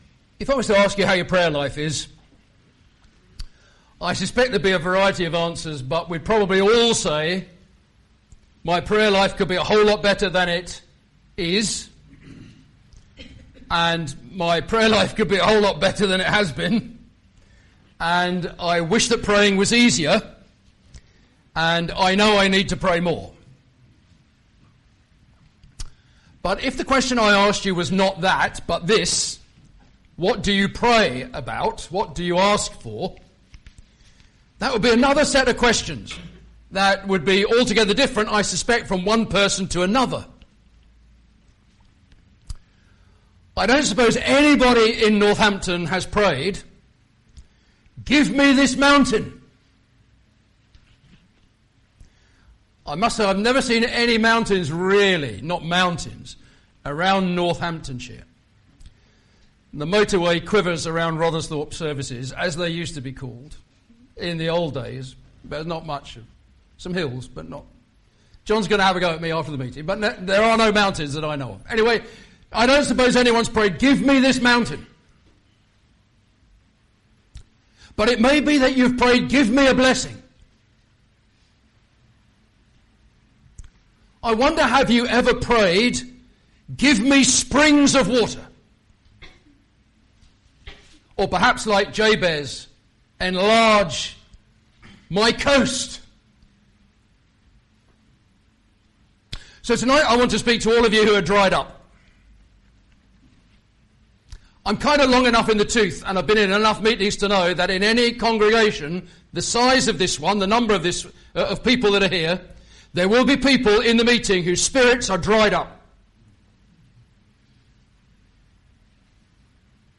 Sermons – The Gospel Hall
1 Chronicles 4:10 Service Type: Ministry